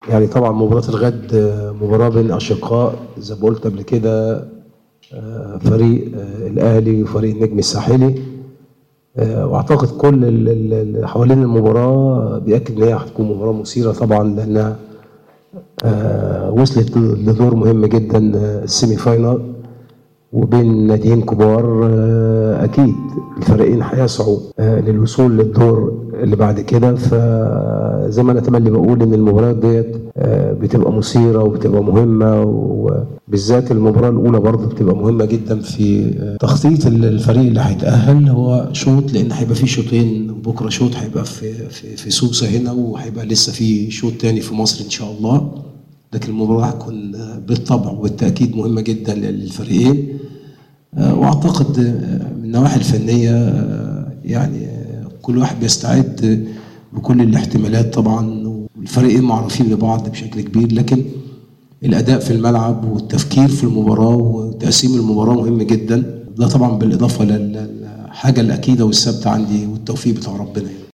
أكد مدرب الأهلي المصري حسام البدري خلال الندوة الصحفية التي عقدها اليوم قبل الحصة التدريبية الأخيرة بملعب سوسة أنه يحترم النجم الساحلي جدا و هو على دراية بإمكانياته لكنه سيسعى للعودة إلى القاهرة بنتيجة إيجابية قبل مقابلة الإياب التي ستكون حاسمة .